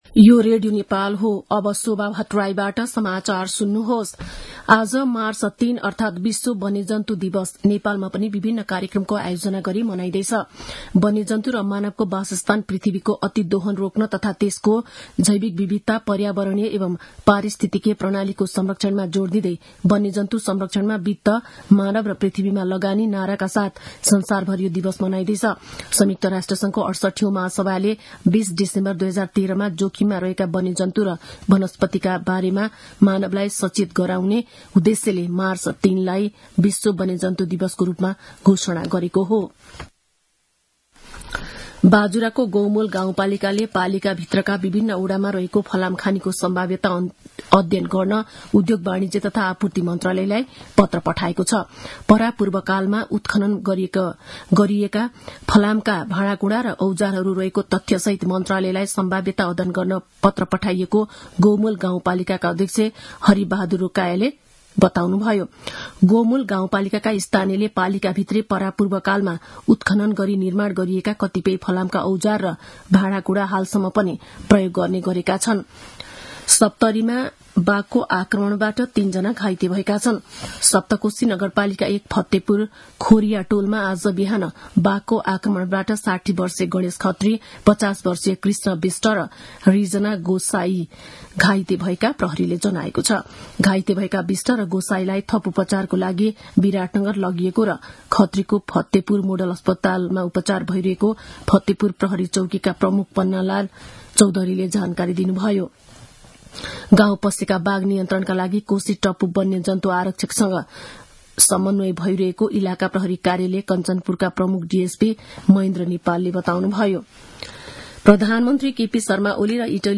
दिउँसो १ बजेको नेपाली समाचार : २० फागुन , २०८१
1-pm-news.mp3